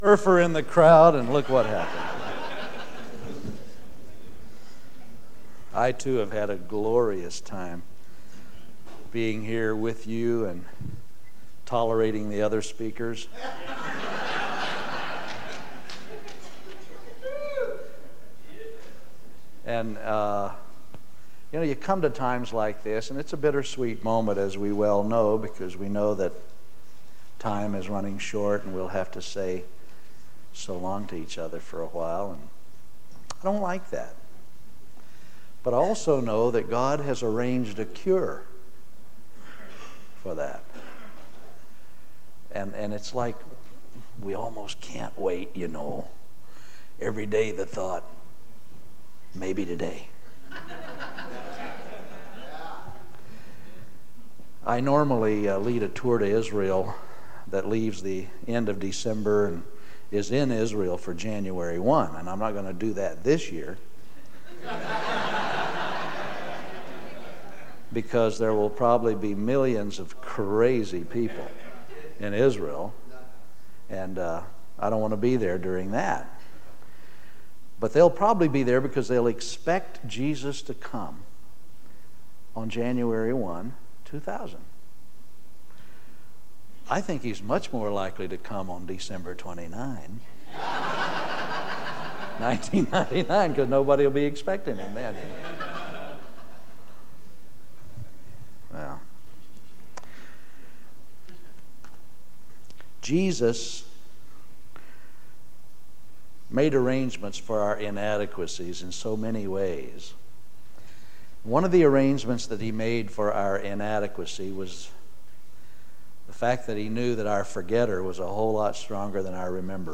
Home » Sermons » 1999 DSPC: Session 12